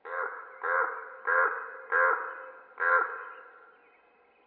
crow_leise2.mp3